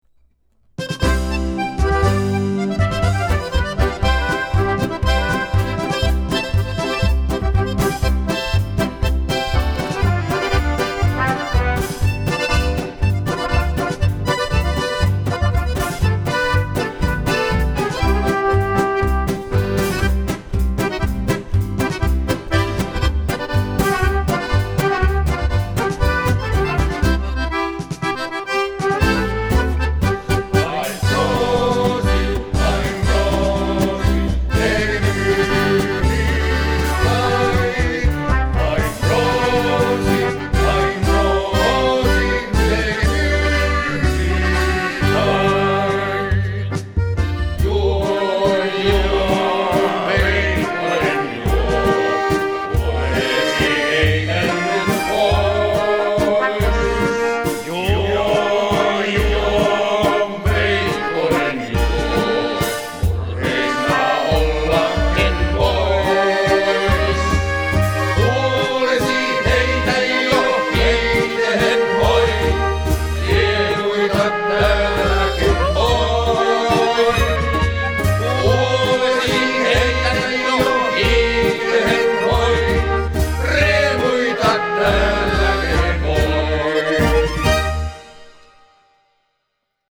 kitara / laulu
haitari
basso
rummut
• Tanssiorkesteri